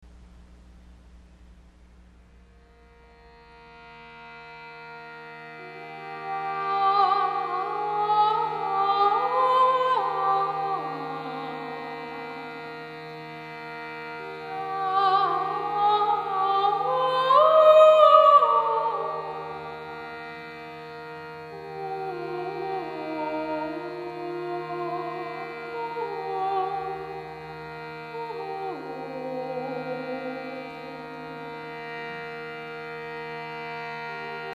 Candlelight Meditation Concert
Concert Excerpts -
Wafting Breeze with an Indian Schurti Box